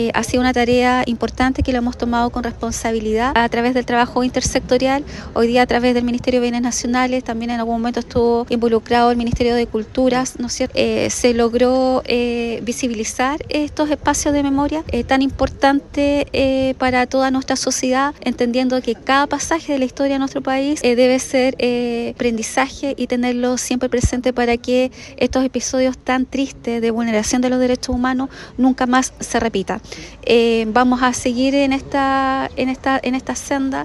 La delegada presidencial provincial de Osorno, Claudia Pailalef, indicó que este tipo de iniciativas buscan mantener viva la memoria histórica y reforzar el compromiso institucional.